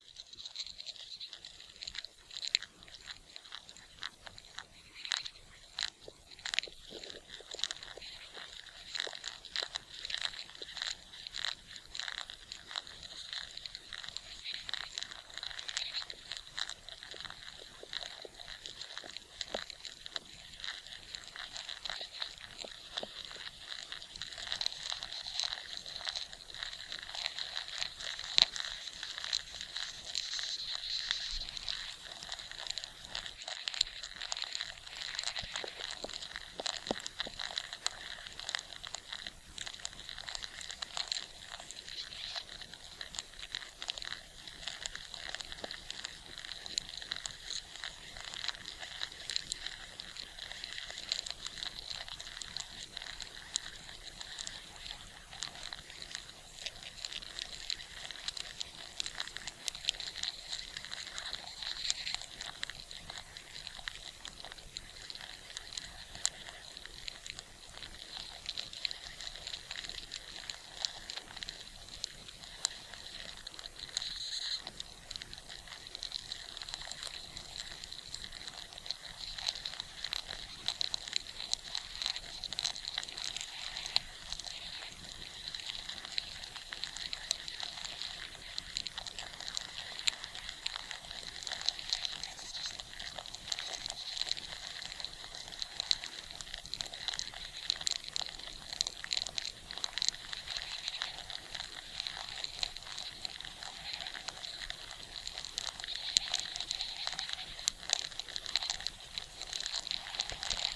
animals / ants